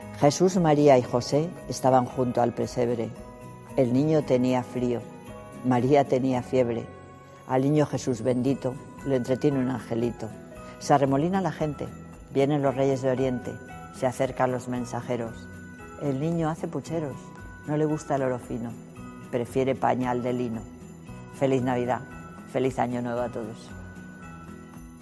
"Aveces la ciudad nos deja demasiado anclados en la soledad. Queremos combatir la soledad no deseada. Que el frío no nos arredre para salir a la calle para confraternizar", reivindica, con un guiño, la alcaldesa en su ya tradicional mensaje navideño, de algo más de cinco minutos de duración, grabado en esta ocasión en el patio renacentista del Museo de San Isidro.